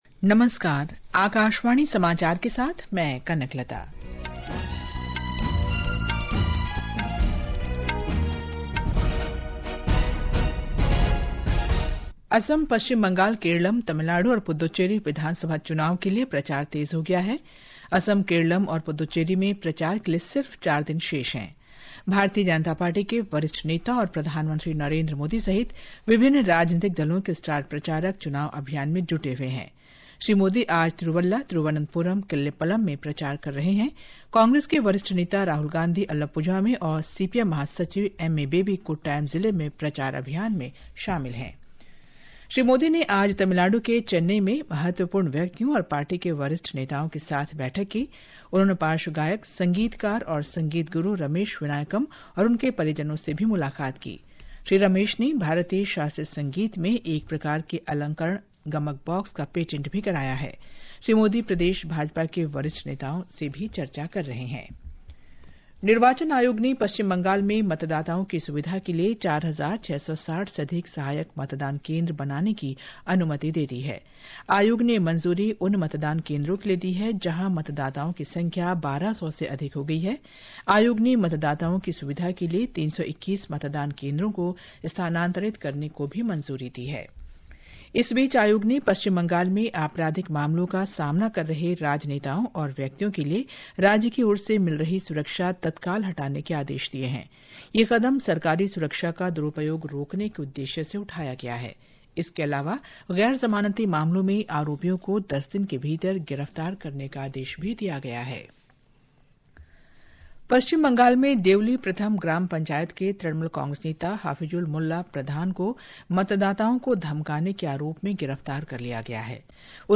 জাতীয় বুলেটিন
प्रति घंटा समाचार